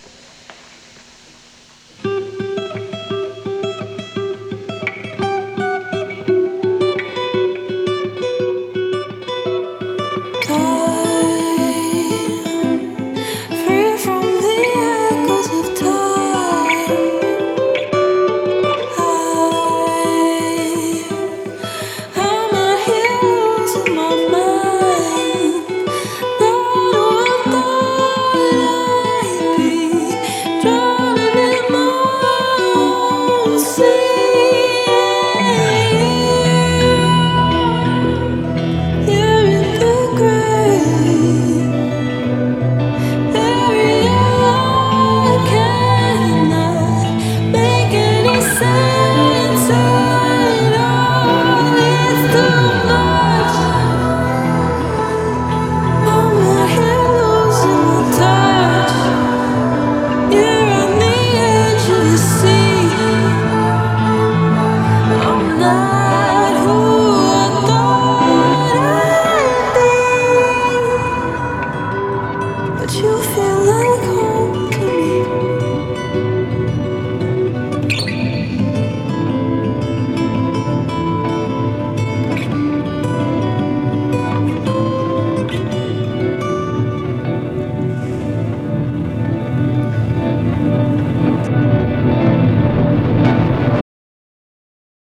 Radio Stinger